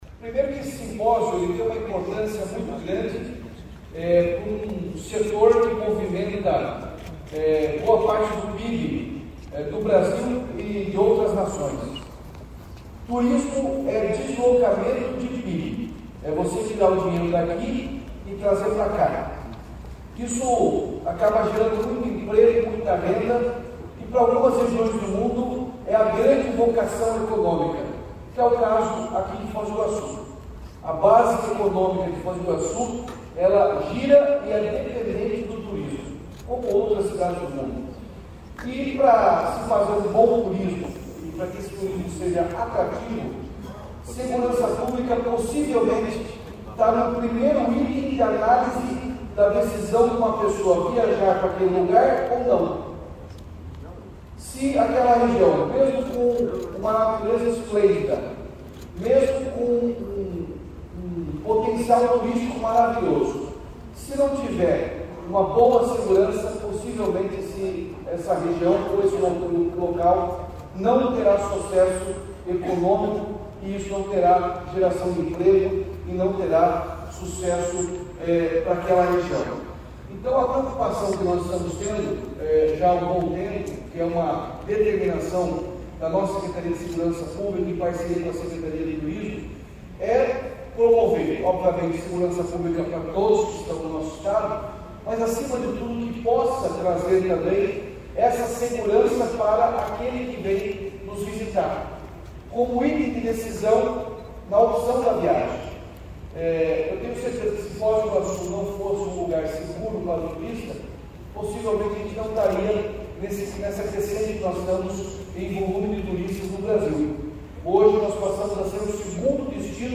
Sonora do governador Ratinho Junior sobre o Simpósio Nacional de Segurança no Atendimento ao Turista